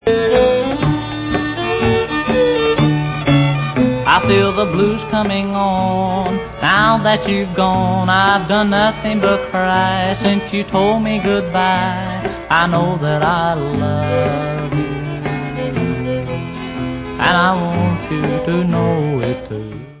sledovat novinky v oddělení Rock & Roll